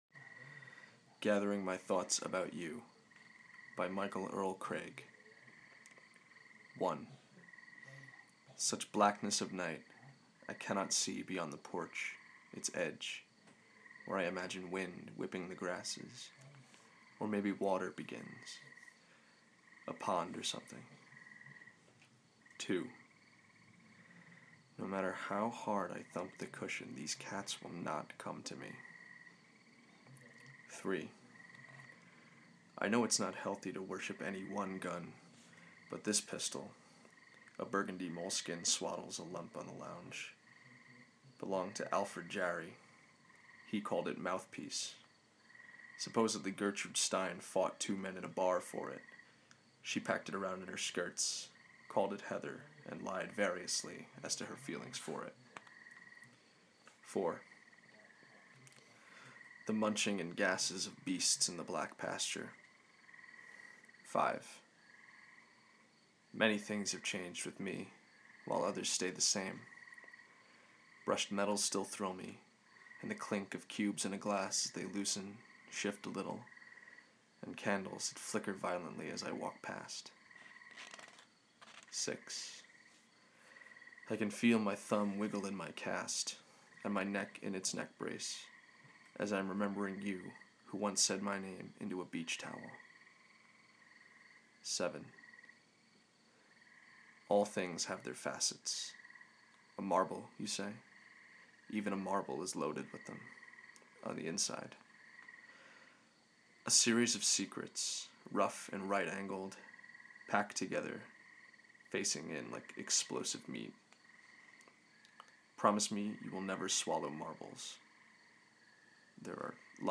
poem, reading, tree frogs